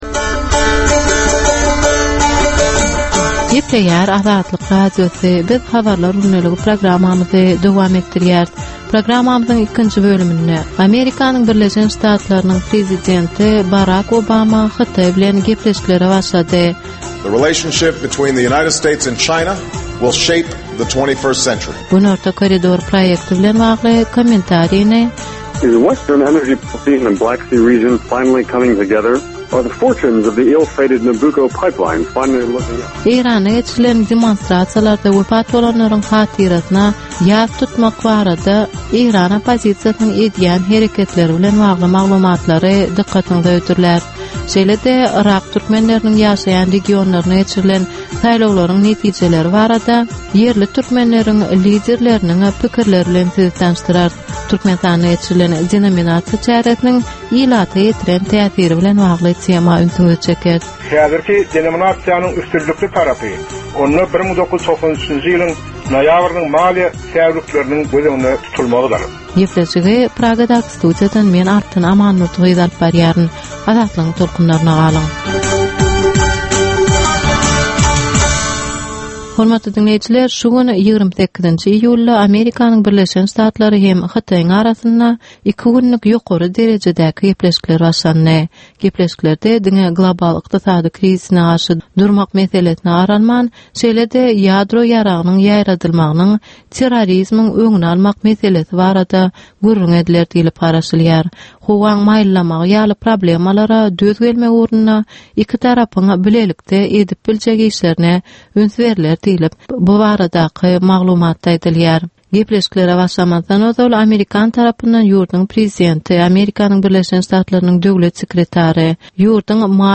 Türkmenistandaky we halkara arenasyndaky sonky möhüm wakalar we meseleler barada ýörite informasion-analitiki programma. Bu programmada sonky möhüm wakalar we meseleler barada ginisleýin maglumatlar, analizler, synlar, makalalar, söhbetdeslikler, reportažlar, kommentariýalar we diskussiýalar berilýär.